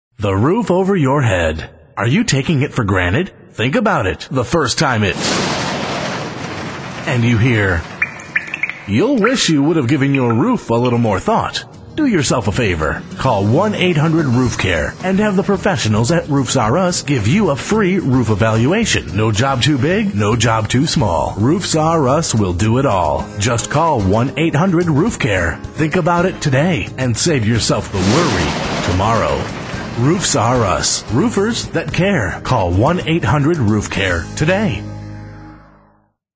As a member, for a one-time editing fee we will customize these radio spots with your company's name and information.